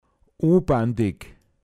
pinzgauer mundart
u(n)bandeg unbändig